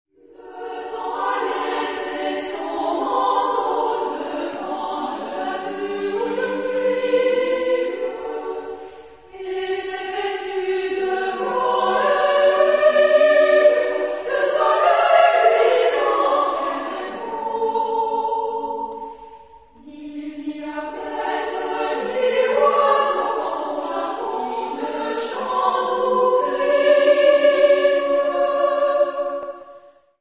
Genre-Style-Form: Partsong ; Contemporary ; Secular
Type of Choir: SSA  (3 women voices )
Tonality: D major